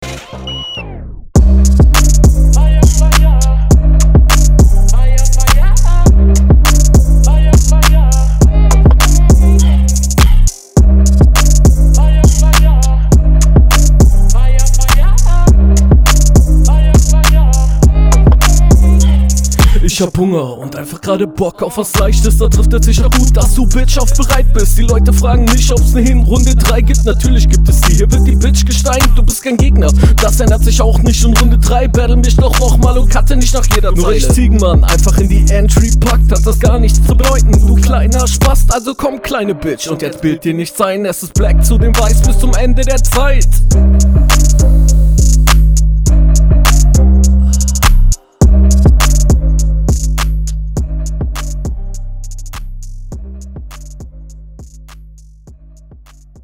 Ok allem vorran erstmal der beat schallert ja mal anderst holy shit Flow: flowst hier …
Cooler Beat, aber hier selbe Problem wie RR2, zieh dein Image durch, sonst Battle als …